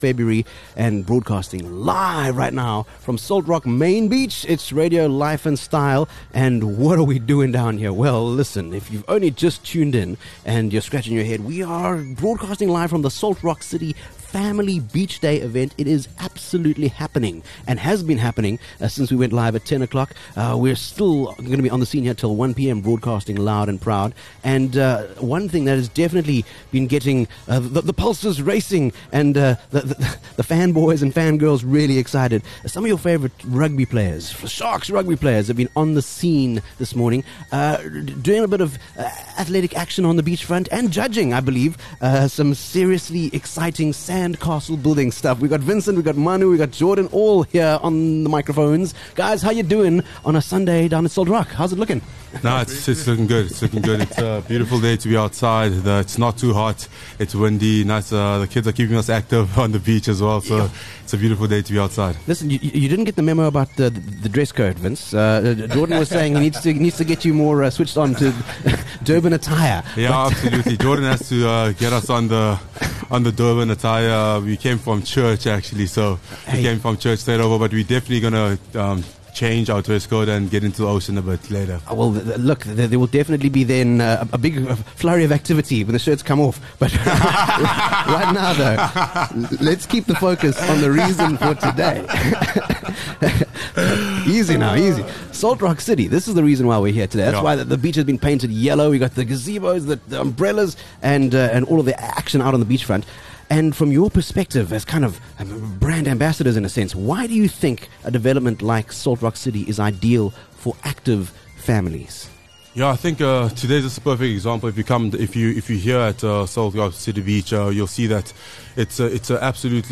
23 Feb Live from Salt Rock Main Beach: The Salt Rock City Family Beach Day